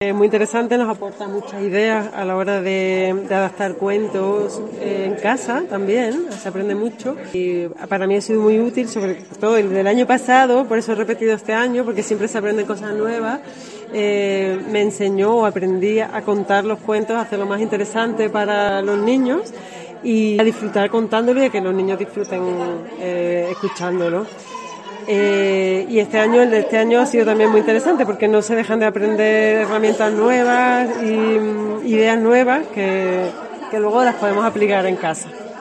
Una mamá que repetía por segunda vez,